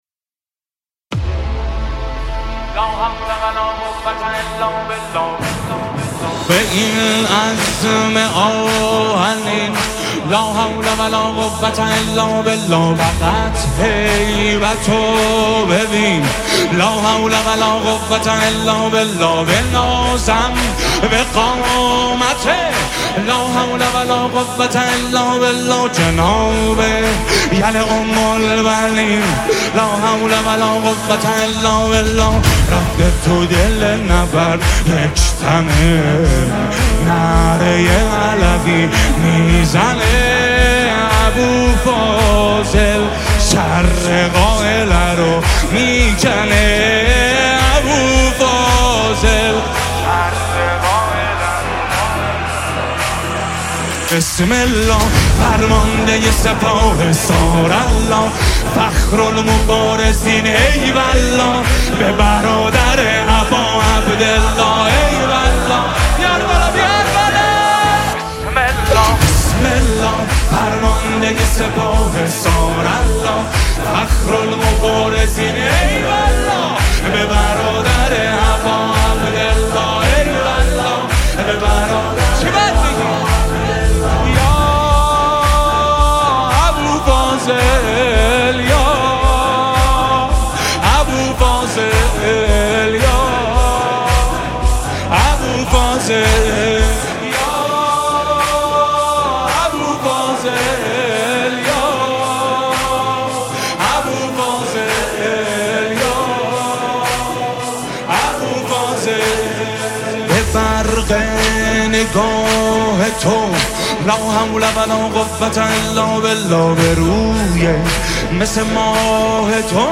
نماهنگ حماسی